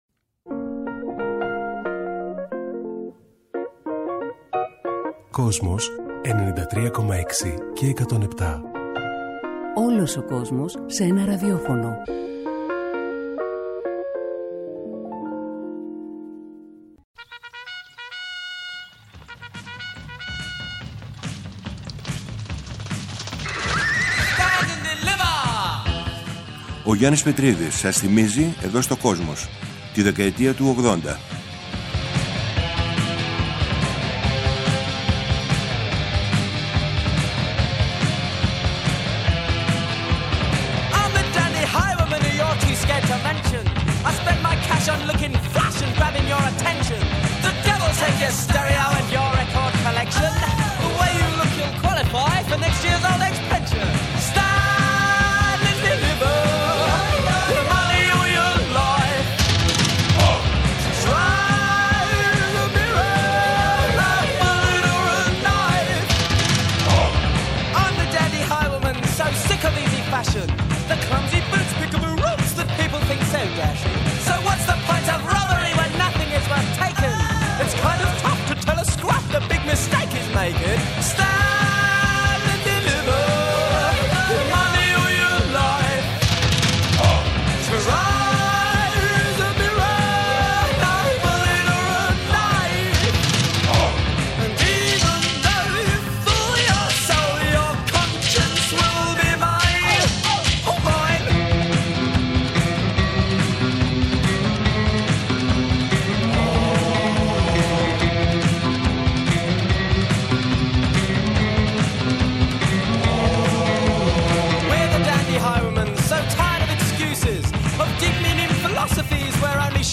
Μετά την ολοκλήρωση του αφιερώματος στη μουσική του 21ου αιώνα, ο Γιάννης Πετρίδης παρουσιάζει ένα νέο μουσικό αφιέρωμα στην 20ετία 1980-2000, από την Κυριακή 14 Μαρτίου 2021 και κάθε Κυριακή στις 19:00 στο Kosmos 93.6. Παρουσιάζονται, το ξεκίνημα της rap, η μεταμόρφωση του punk σε new wave, οι νεορομαντικοί μουσικοί στην Αγγλία, καθώς και οι γυναίκες της pop στην Αμερική που άλλαξαν τη δισκογραφία.